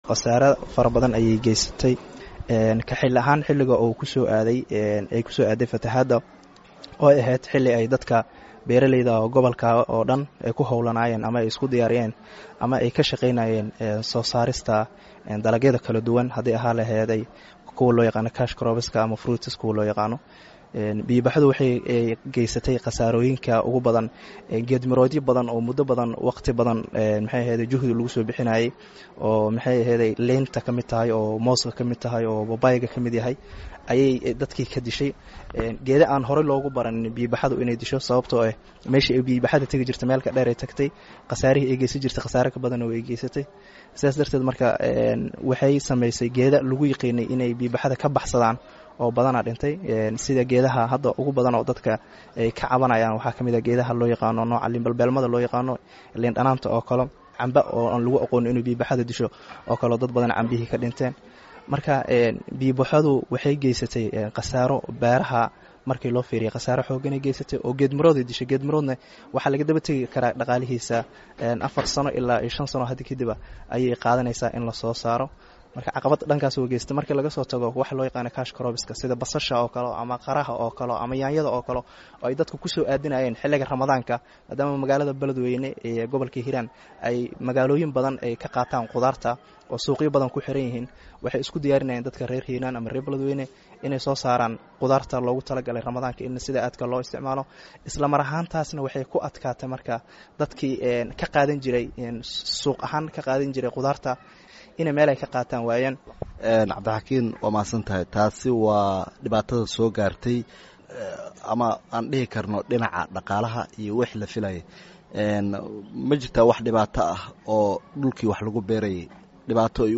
Wareysi: Saameynta fatahaadda ee beeraha